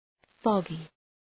Shkrimi fonetik {‘fɒgı}